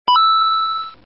tf2gg_multikill.mp3